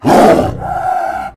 CosmicRageSounds / ogg / general / combat / creatures / tiger / he / taunt1.ogg